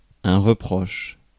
Ääntäminen
IPA: [ʁə.pʁɔʃ]